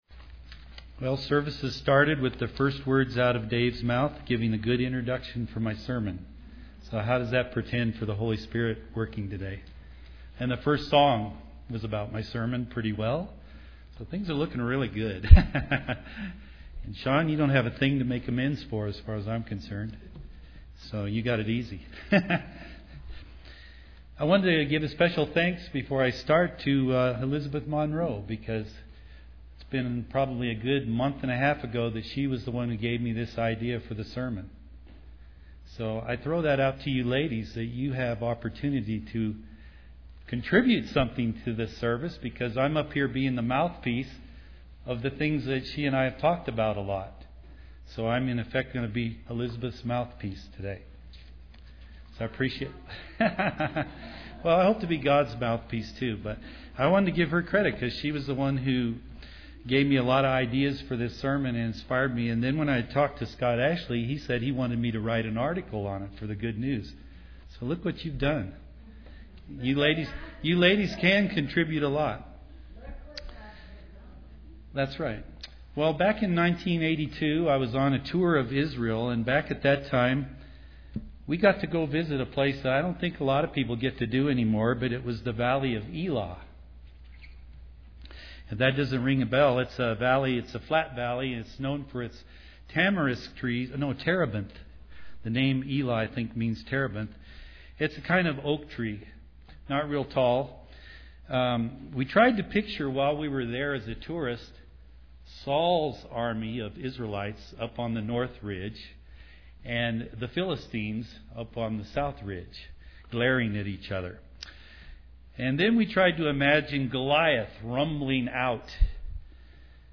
Given in Colorado Springs, CO
UCG Sermon Studying the bible?